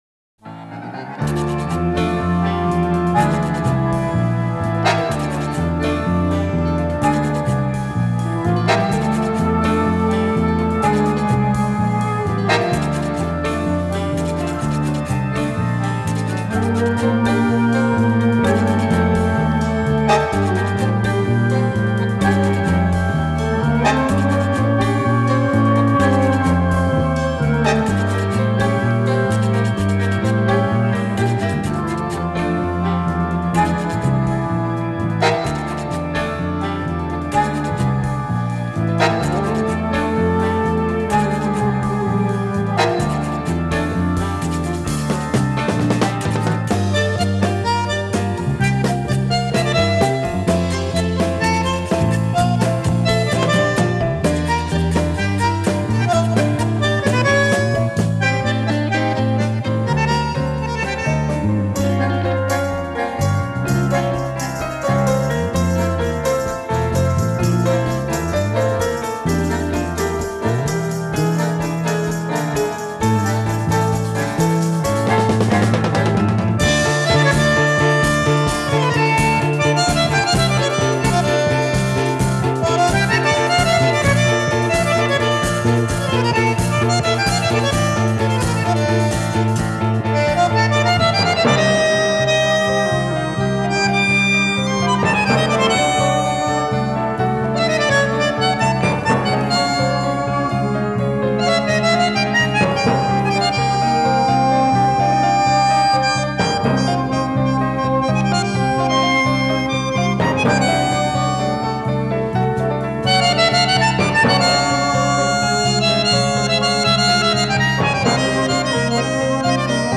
Танго
bandoneon
Recorded in Milan, Italyin May 1974